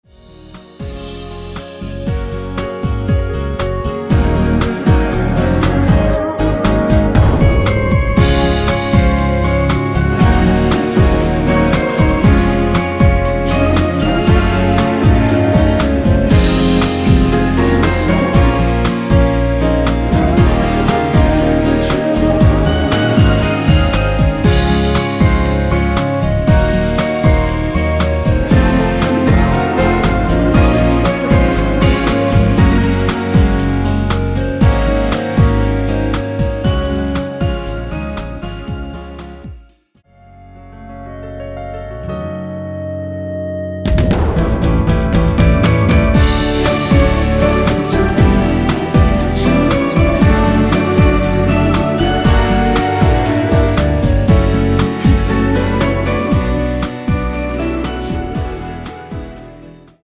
원키에서(-2)내린 멜로디와 코러스 포함된 MR 입니다.(미리듣기 참조)
Db
앞부분30초, 뒷부분30초씩 편집해서 올려 드리고 있습니다.
중간에 음이 끈어지고 다시 나오는 이유는